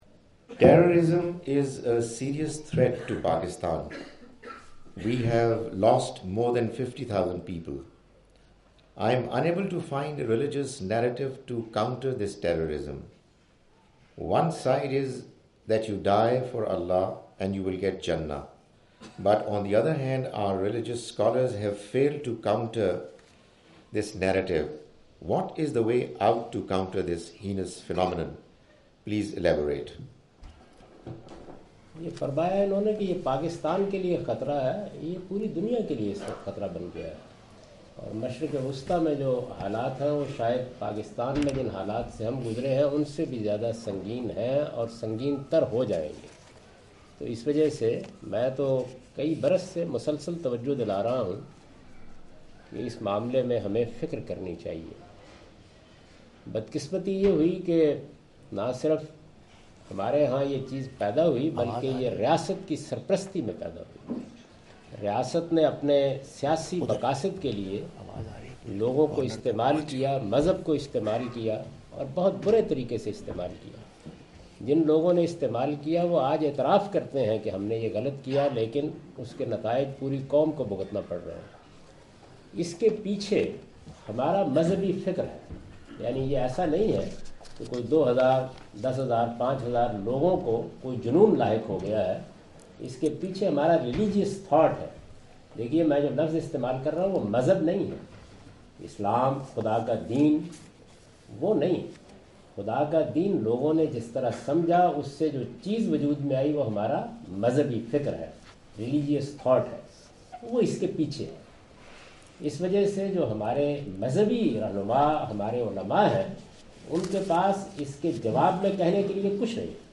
Javed Ahmad Ghamidi answer the question about "narrative to counter terrorism" during his visit to Manchester UK in March 06, 2016.
جاوید احمد صاحب غامدی اپنے دورہ برطانیہ 2016 کے دوران مانچسٹر میں "دہشت گردی کے لیے جوابی بیانیہ" سے متعلق ایک سوال کا جواب دے رہے ہیں۔